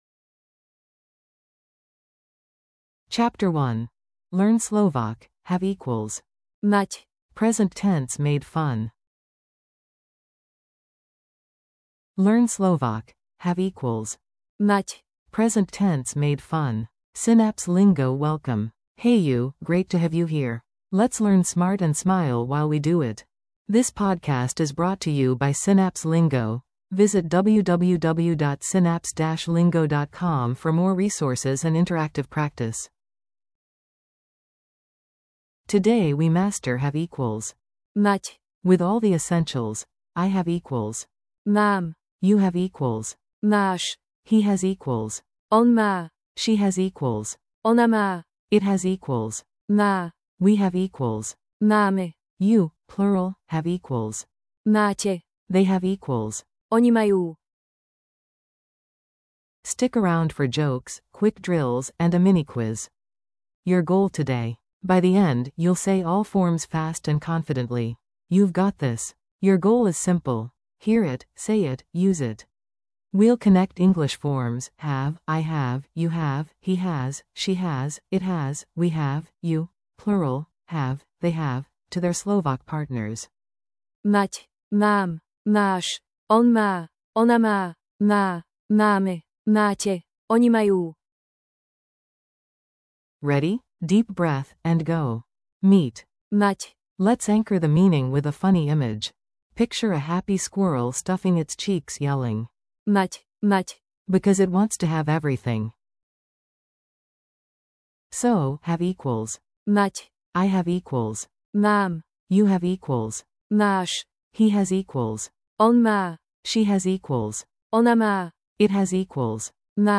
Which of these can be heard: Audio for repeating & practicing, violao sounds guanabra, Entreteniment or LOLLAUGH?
Audio for repeating & practicing